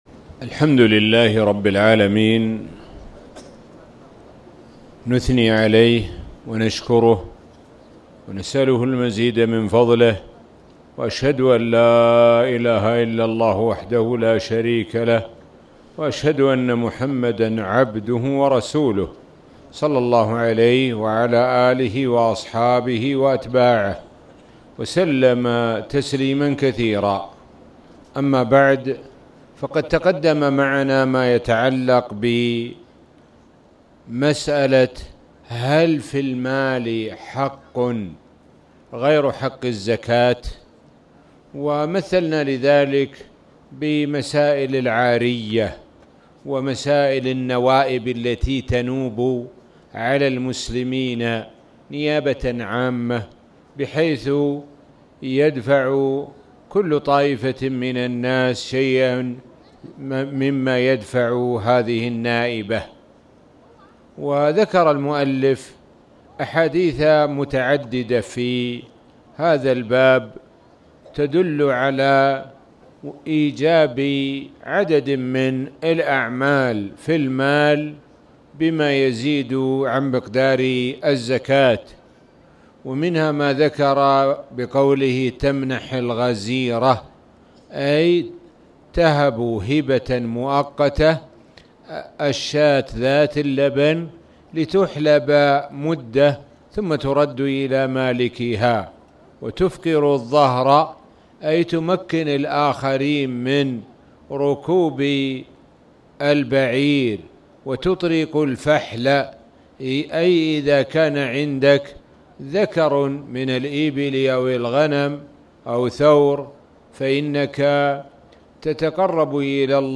تاريخ النشر ١٨ رمضان ١٤٣٨ هـ المكان: المسجد الحرام الشيخ: معالي الشيخ د. سعد بن ناصر الشثري معالي الشيخ د. سعد بن ناصر الشثري باب الصدقة على أهل الذمة